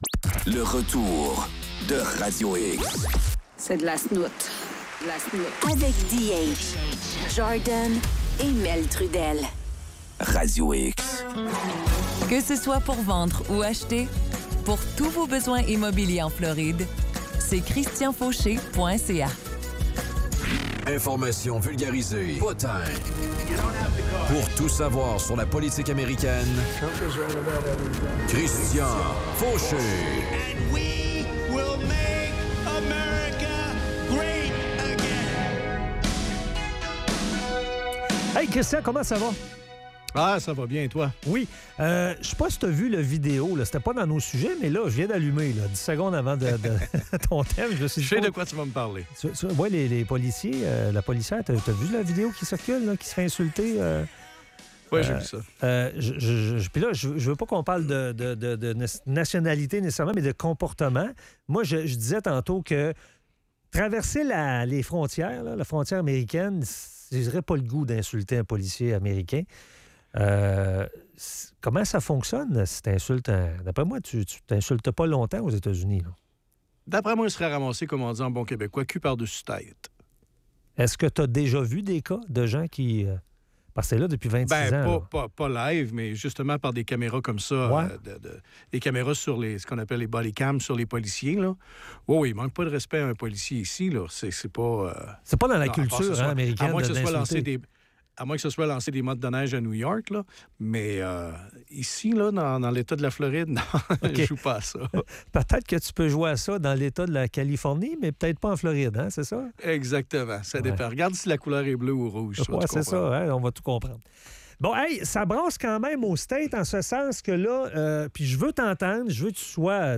La chronique